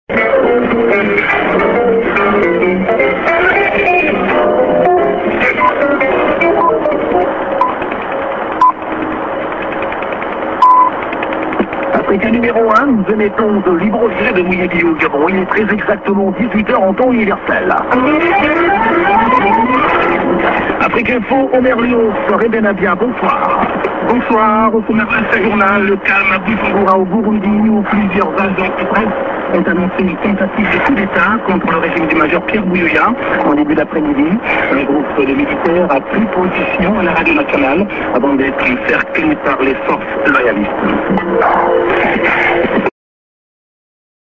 Mid. Music->TS->ID(men)->SJ->